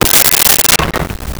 Glass Bottle Break 02
Glass Bottle Break 02.wav